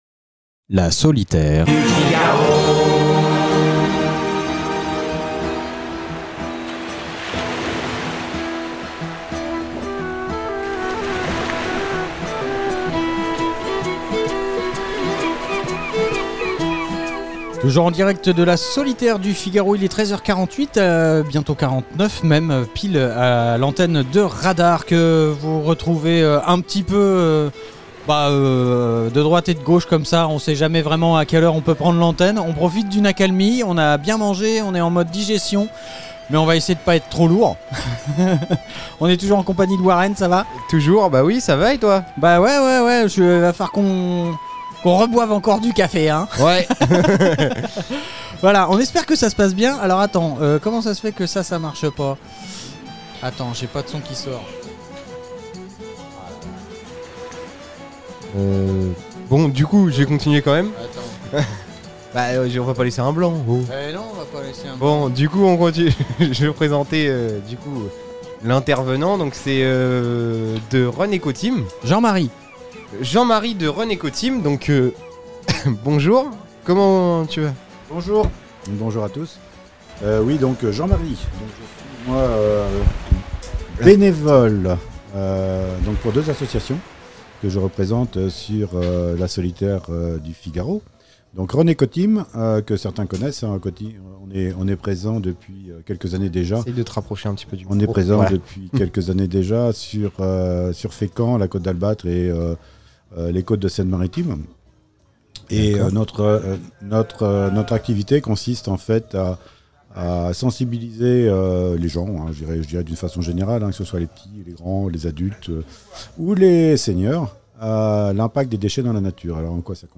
Le studio mobil est une caravane entièrement équipée pour produire des émissions couvrant différents événements. On y reçoit les acteurs et participants de ces manifestations pour les interviewer en direct (quand les connexions sont possibles) ou en conditions de direct, ce qui donne de nombreux podcasts à retrouver ici.